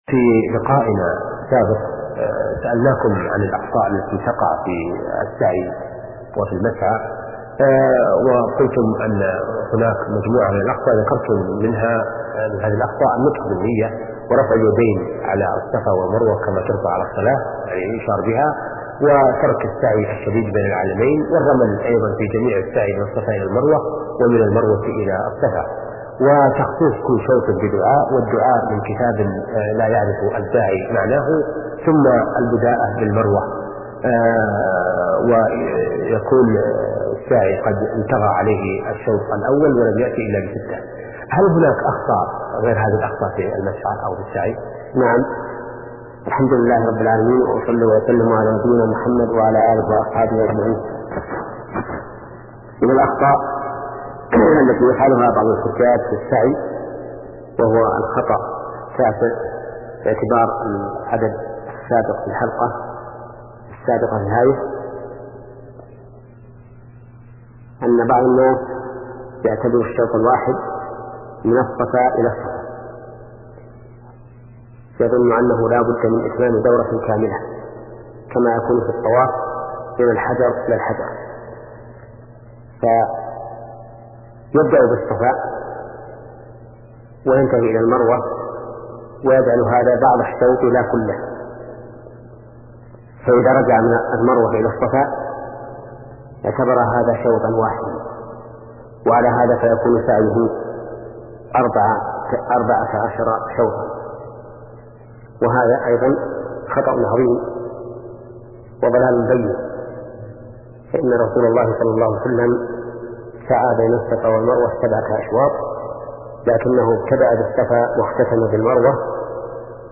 شبكة المعرفة الإسلامية | الدروس | فقه العبادات (52) |محمد بن صالح العثيمين